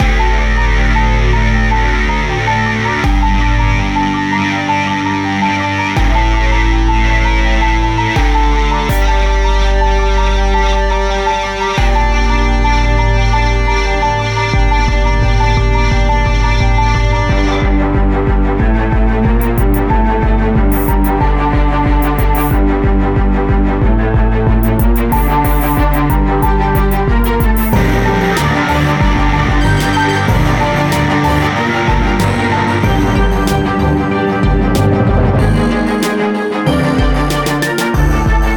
For the rough mix, the drums typically sit on the same track to save CPU power, like the examples in this article.
The bass drum frequency is lower than the original bass drum.
Not only is the bass drum too low, the cymbal frequency is high and “tinny”, making for an awkward combination of timbres and sounds.
In addition, the bass drum’s release is too long, causing notes to run into each other.
The bass drum adds muddiness and is not a good choice for this particular mix.